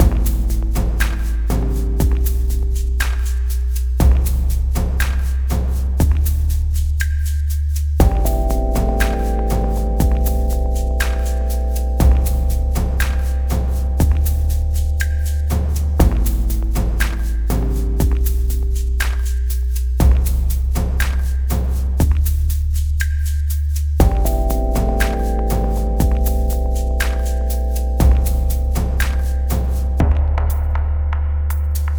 A short ambiance track which would be fit for a puzzle game or a menu or something like that (hence the title).